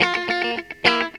GTR 71 EM.wav